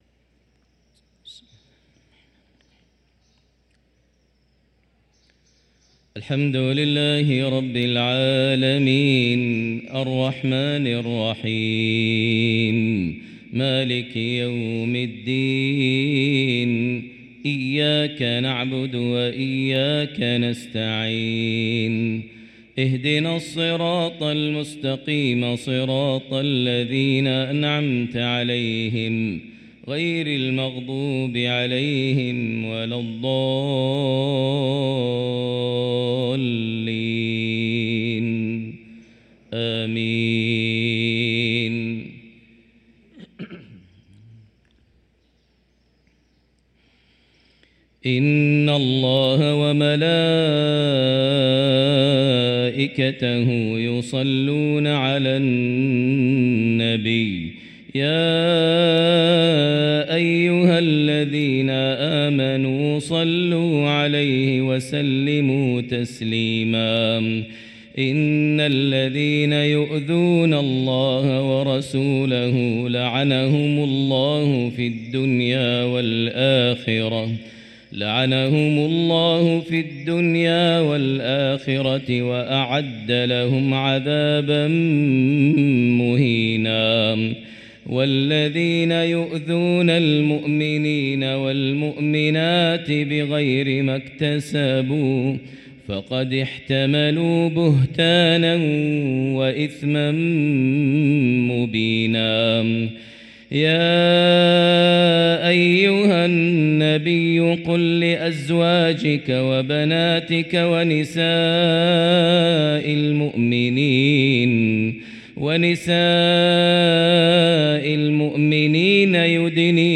صلاة الفجر للقارئ ماهر المعيقلي 7 رجب 1445 هـ